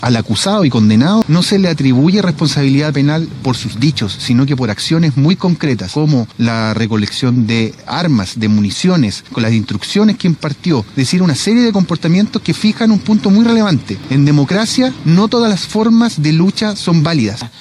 Estas fueron las palabras del fiscal regional de La Araucanía, Roberto Garrido, respecto a los argumentos que utilizó el Ministerio Público para perseguir penalmente al comunero mapuche Héctor Llaitul y lograr un fallo, hasta ahora, inédito: 23 años de cárcel.